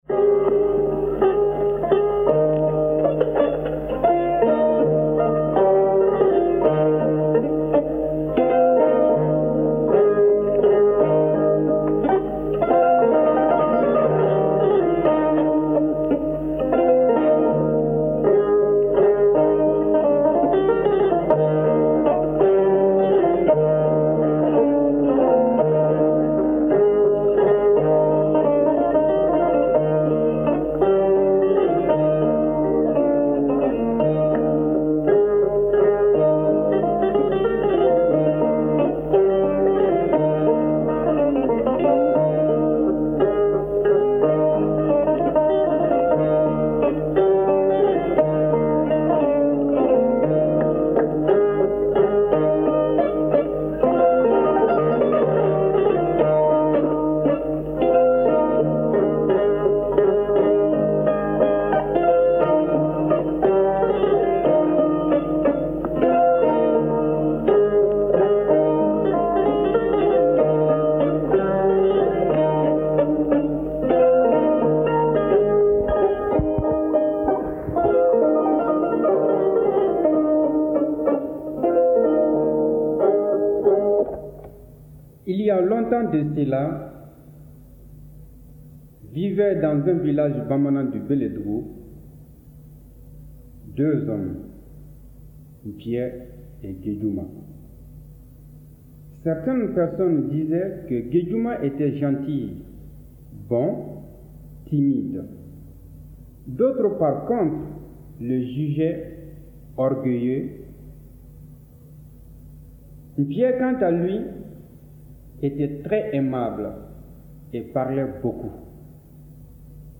Les deux amis : Conte malien · OmekaS By DataCup · Omekas - Mali